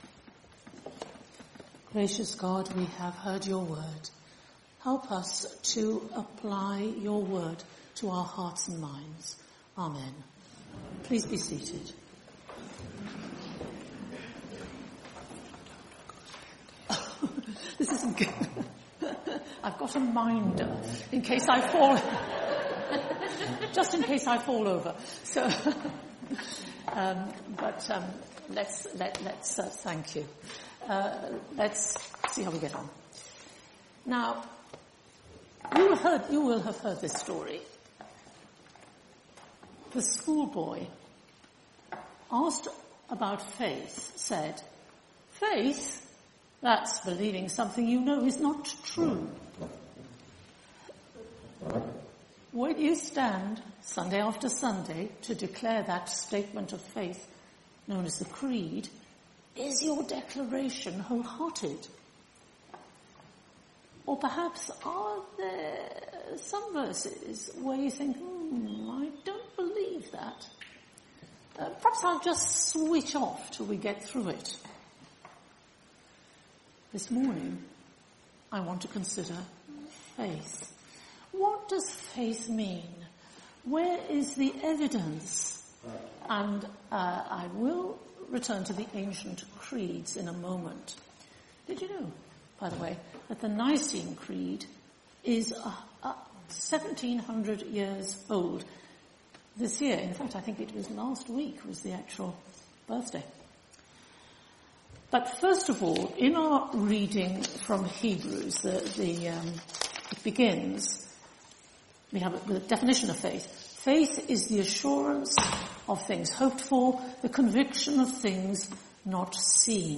This is the Gospel of the Lord All Praise to you, O Christ Series: Ordinary Time , Sunday Morning